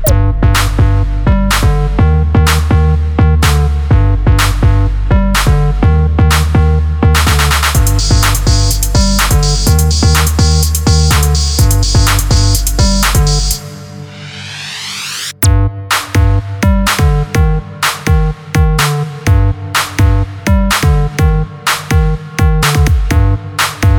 For Solo Male Pop (2000s) 3:41 Buy £1.50